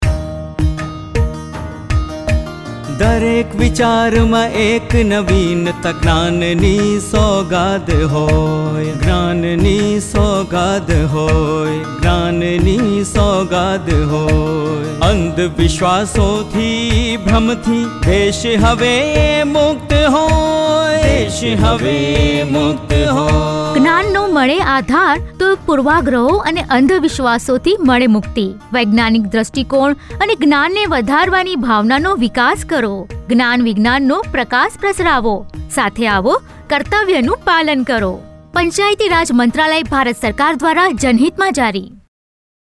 65 Fundamental Duty 8th Fundamental Duty Develop scientific temper Radio Jingle Gujrati